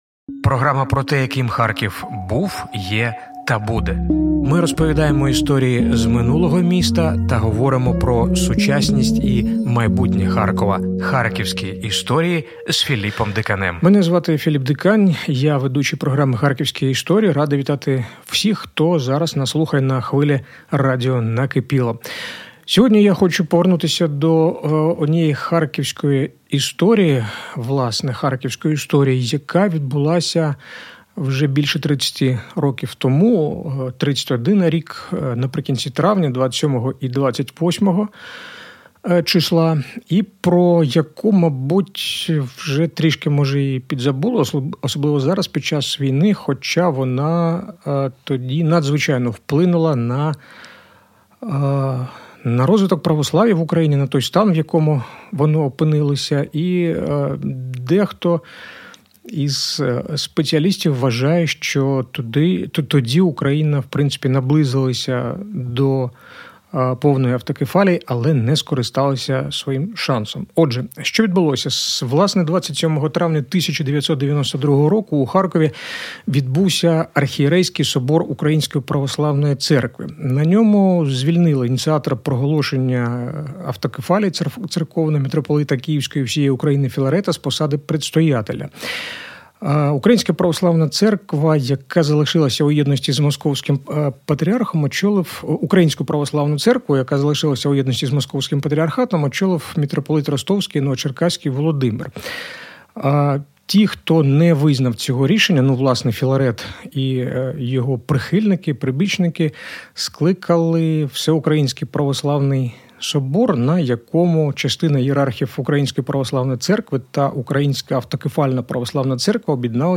Своїми спогадами про події початку 90-х років він поділився в етері радіо «Накипіло».